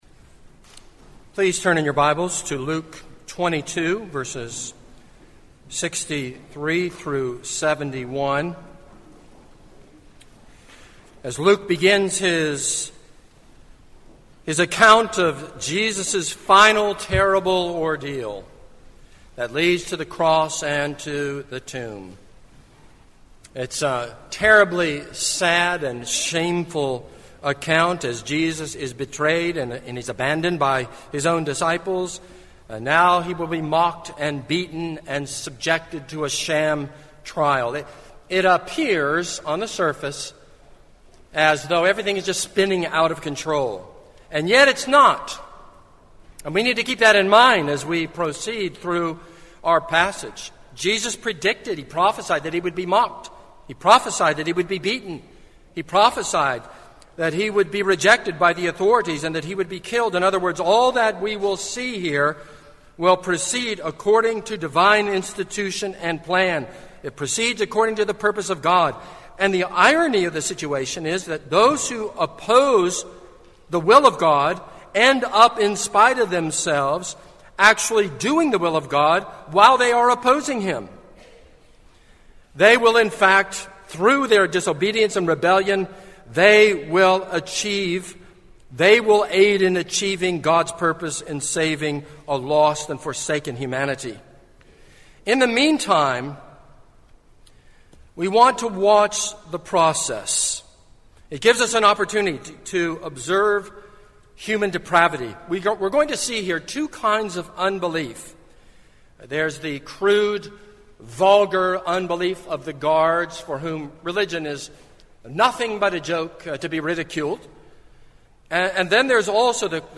This is a sermon on Luke 22:63-71.